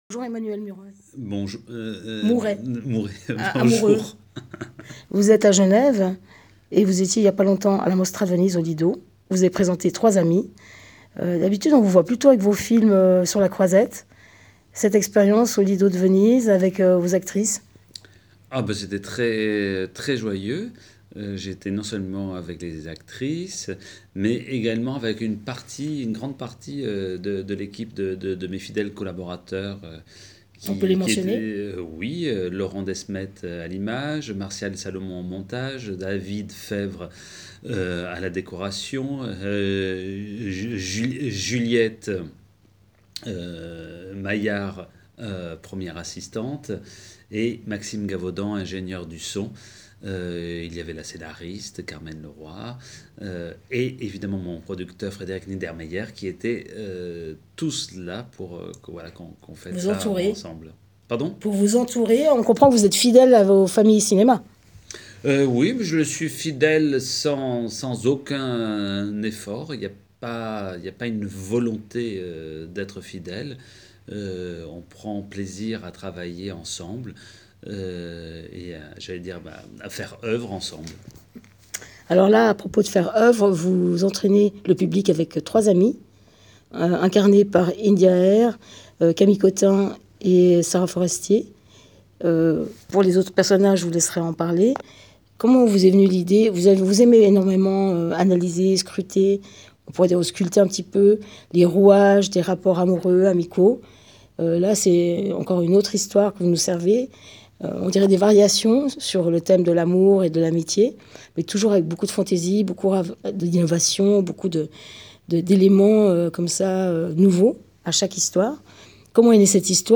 Rencontre - j:mag